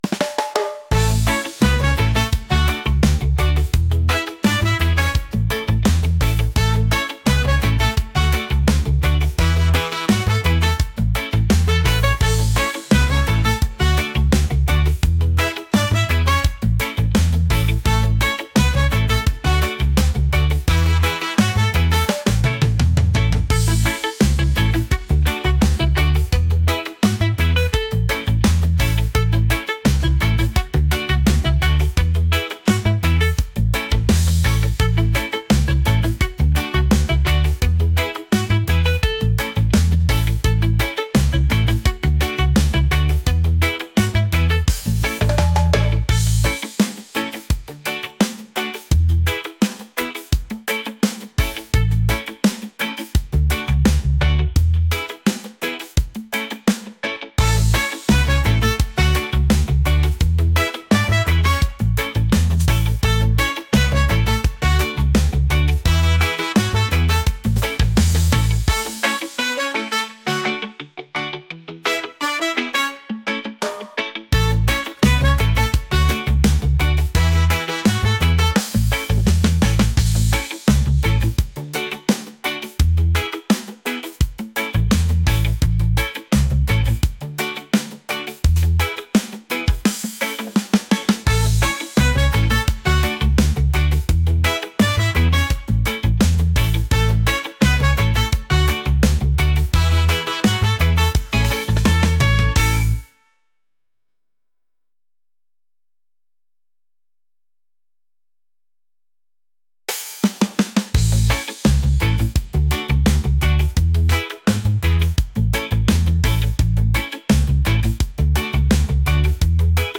upbeat | reggae | catchy